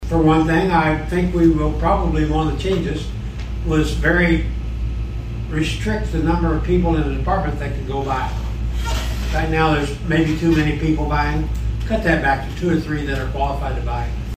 Presiding Commissioner Harold Gallaher says a possible solution is reducing the number of people within a department who are authorized to make purchases.